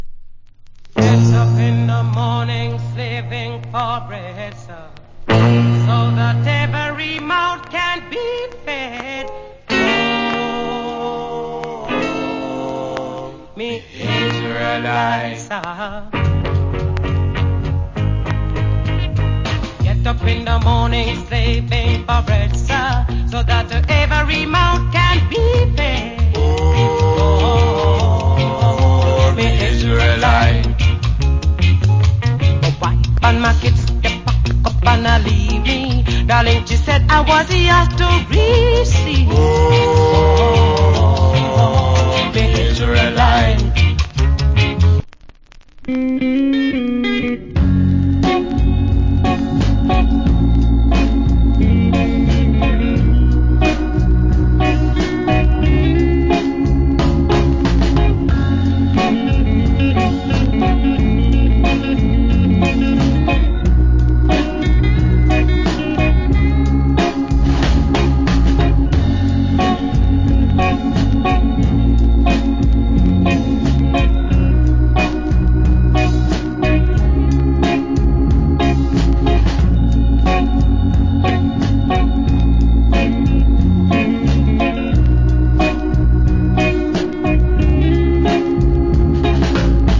Old Hits Early Reggae.